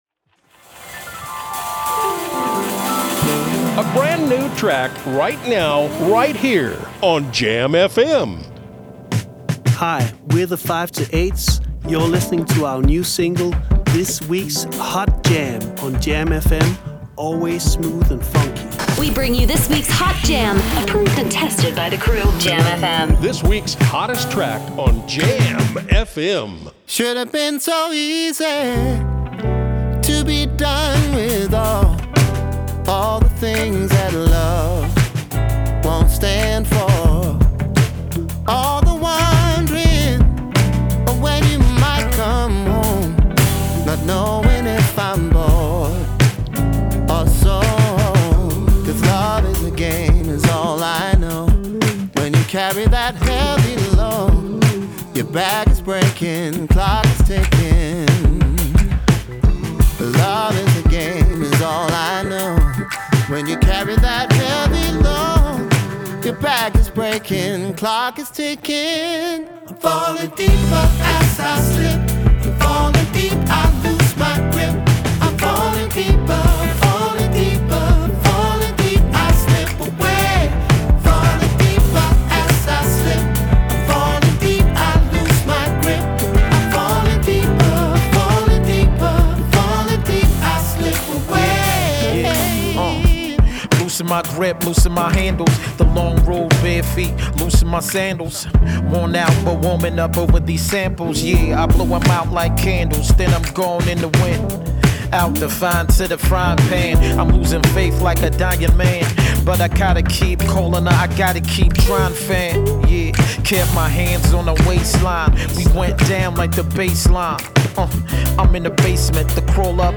rap
zang
basgitaar
toetsen
drums
gitaar